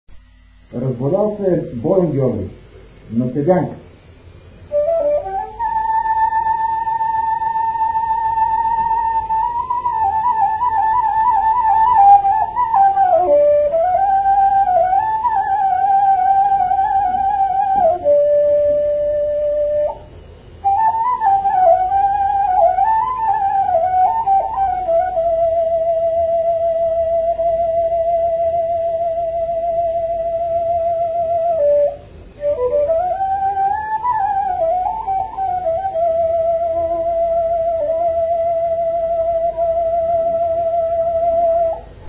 музикална класификация Инструментал
размер Безмензурна
фактура Едногласна
начин на изпълнение Солово изпълнение на кавал
битова функция На седянка
фолклорна област Югоизточна България (Източна Тракия с Подбалкана и Средна гора)
място на записа Бузовград
начин на записване Магнетофонна лента